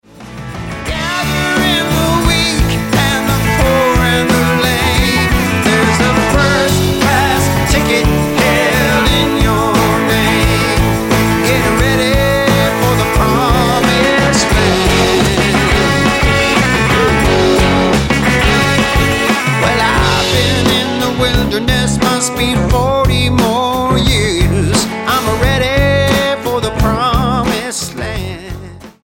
STYLE: Blues
satisfyingly raw collection of original gospel blues numbers